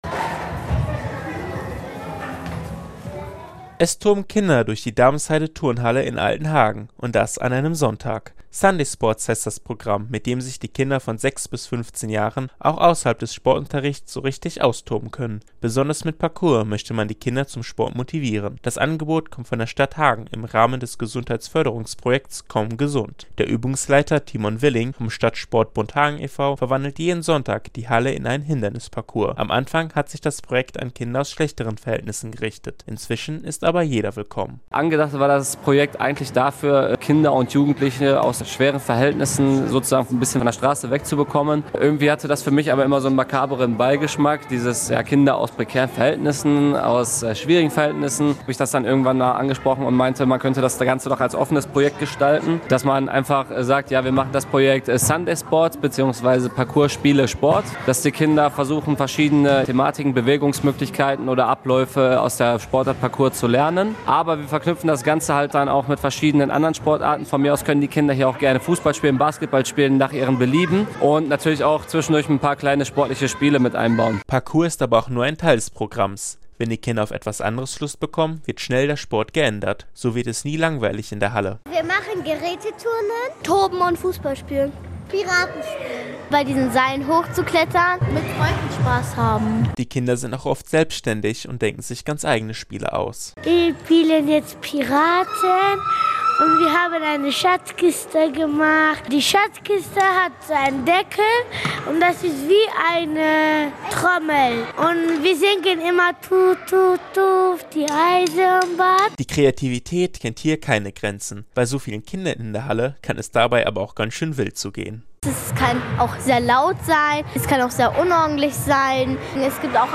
Mittschnitt aus der Sendung